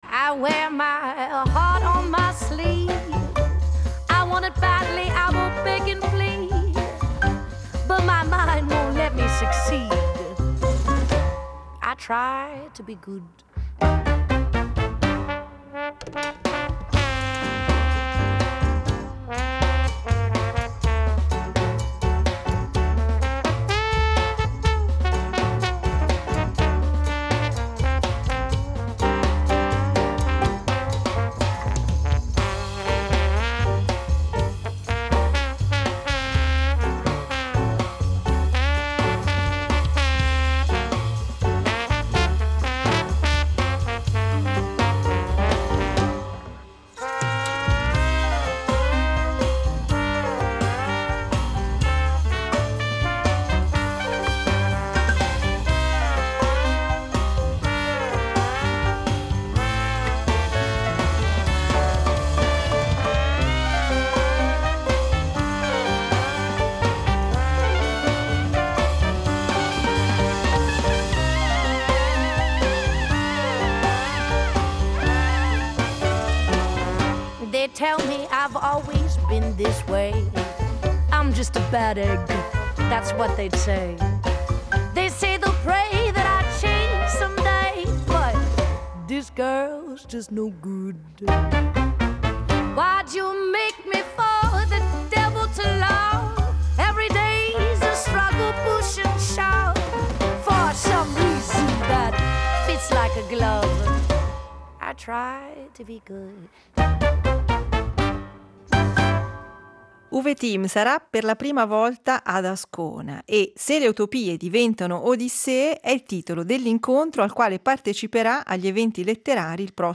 Intervista a Uwe Timm